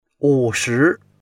wu3shi2.mp3